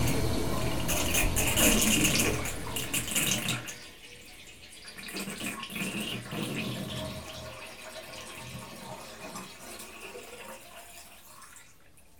bath5.ogg